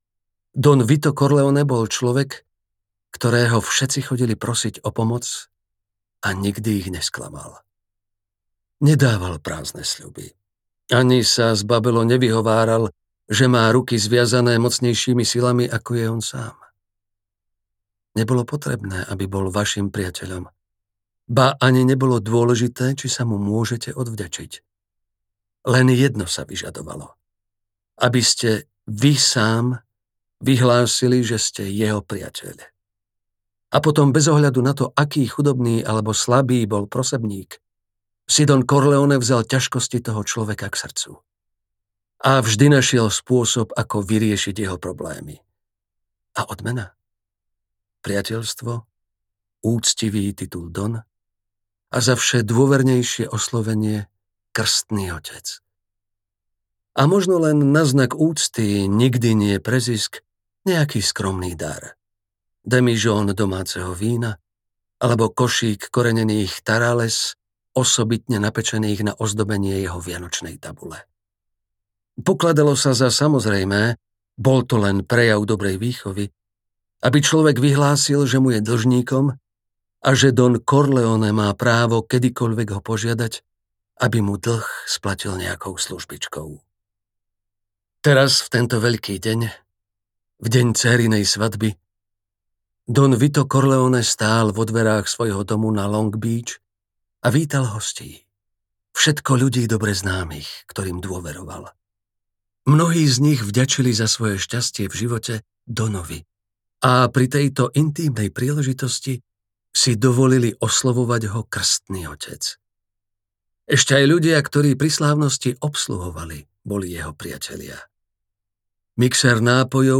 Krstný otec audiokniha
Ukázka z knihy